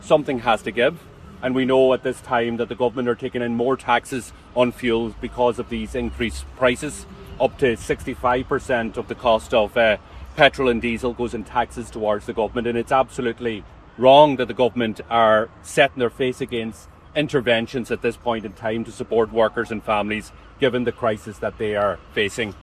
However, Donegal TD and Sinn Fein Finance Spokesperson Pearse Doherty says it’s ‘absolutely wrong’ action isn’t being taken now……………